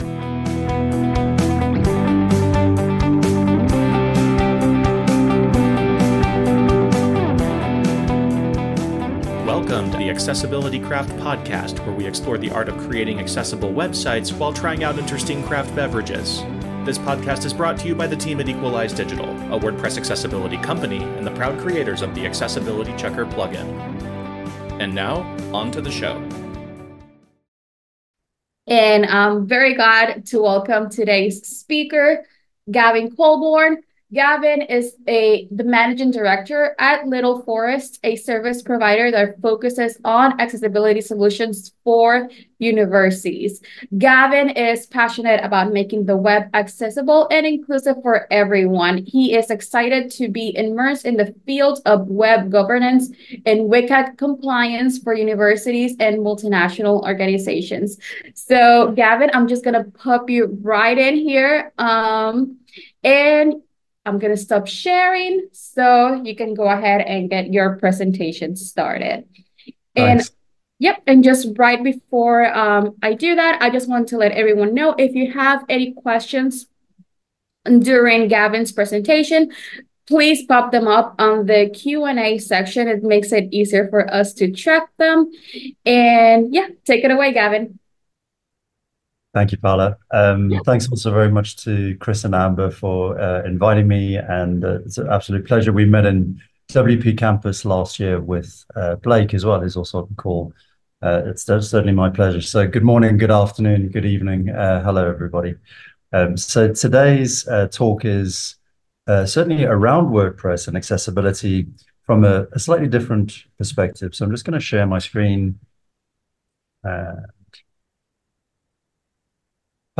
This episode is a recording of a July 2024 WordPress Accessibility Meetup